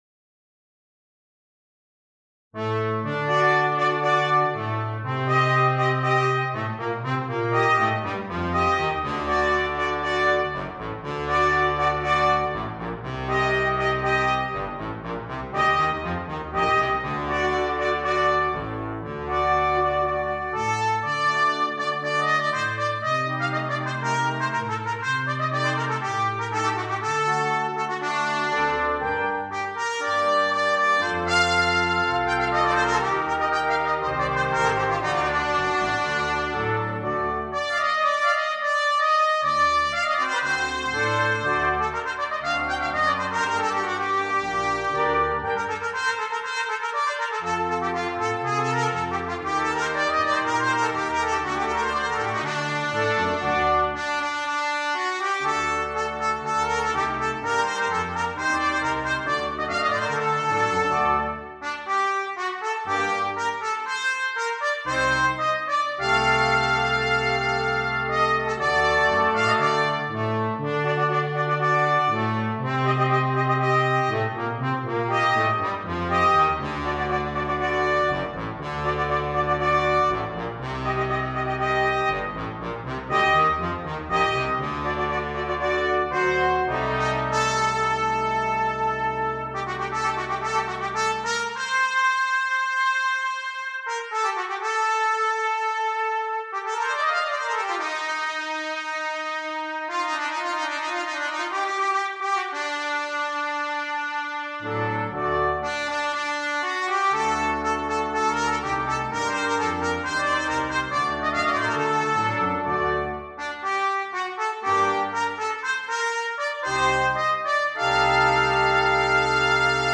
Brass Quintet
Traditional
traditional bullfighting song